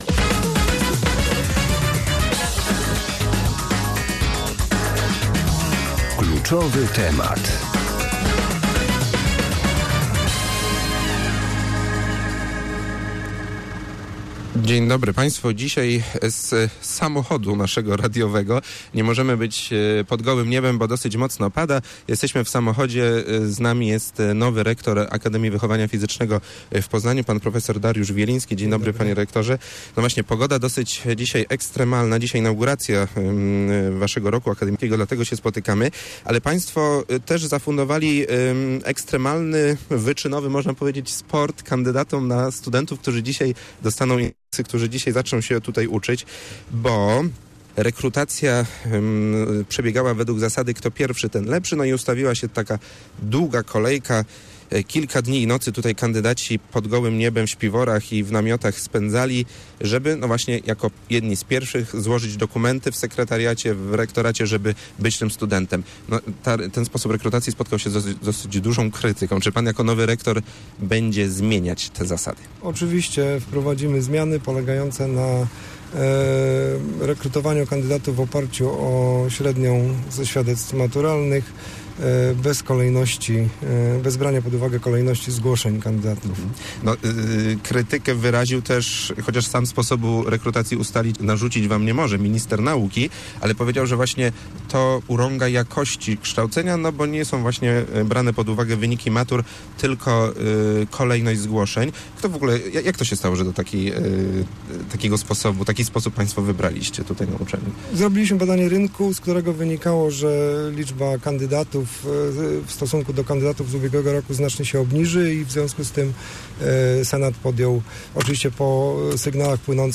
kp2gwc6ekb5xr34_rozmowa_rekrutacja_awf.mp3